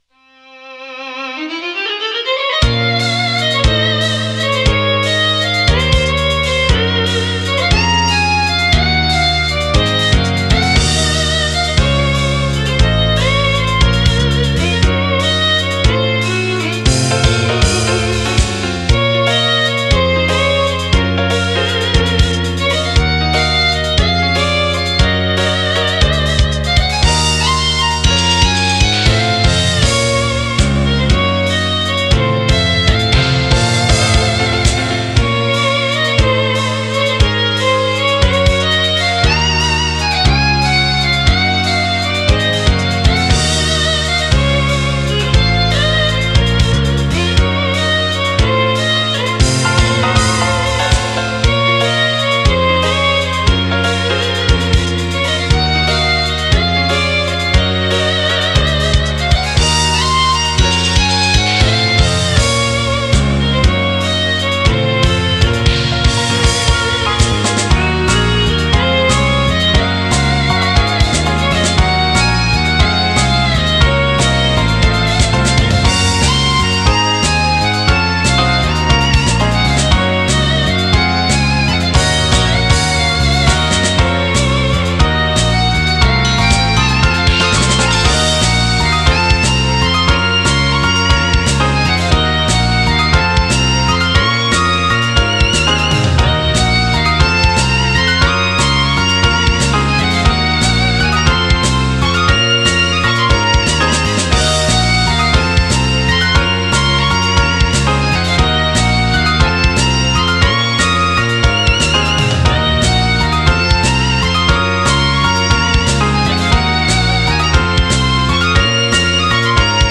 【蒼き記憶】【BGM】未使用曲の裏側 其の２『Breath of Freeze』
ドラマティックな曲だけど合わない・・・・・・。